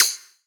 normal-slidertick.wav